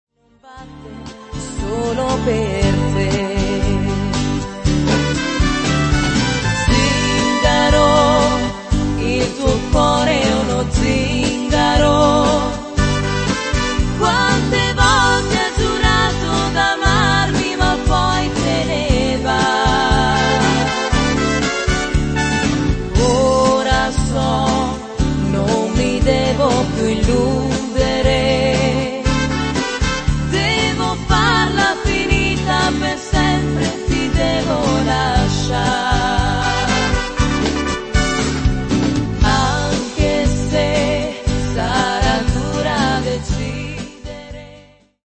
moderato-beguine